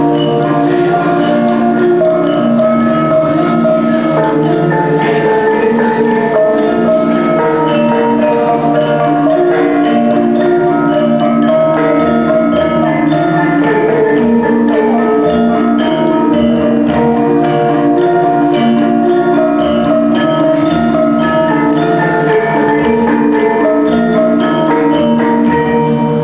私たち１−３は文化祭で中部ジャワに伝わるジャワのガムランを演奏しました。
体育館での発表のようす   　打楽器を主とするインドネシア独自の合奏音楽やその楽器編成のことをガムラン（gameran）といいます。
私たちが今回合奏したのは，ジョグジャの王家に伝わるジャワガムランです。
演奏した曲は，３曲で，いずれもLancaran（ランチャラン）と呼ばれる種類の曲です。
リズムが弾むようで楽しいし，歌詞も好きです。
ゴーン　ゴーンという音がします。
カン　キン　コンととても不思議な音がします。